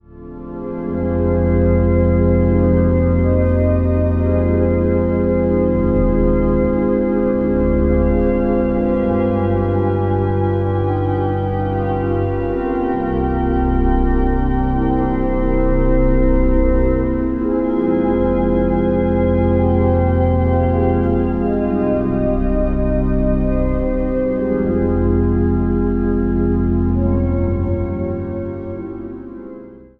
Evangelisch Lutherse Kerk | Den Haag
Instrumentaal | Orgel
Traditioneel